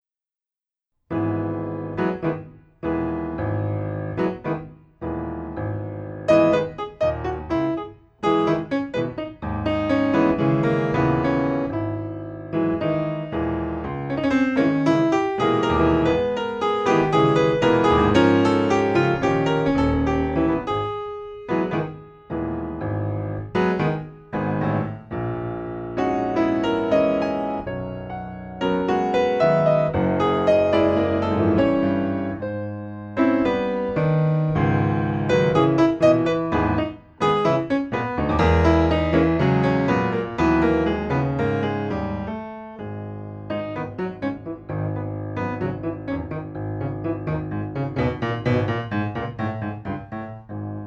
per pianoforte solo